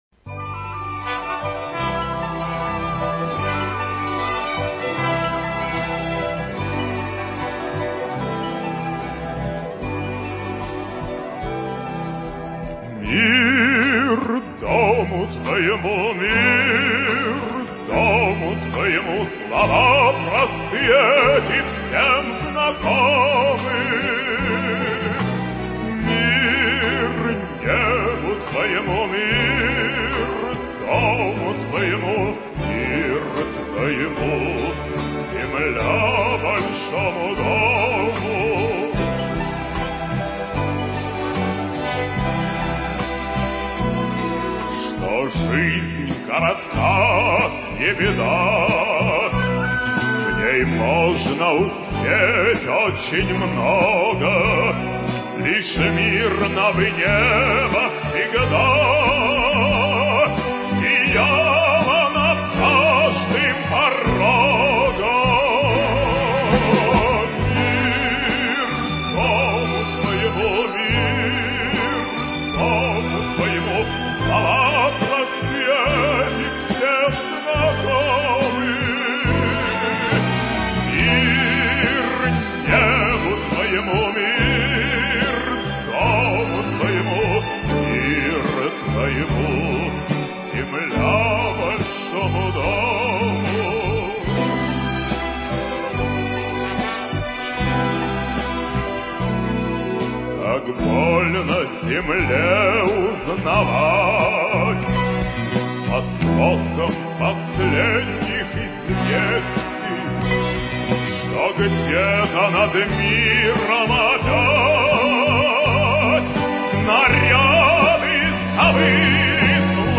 Актуальное интервью: Умный дом от Ростелеком 14.04.2021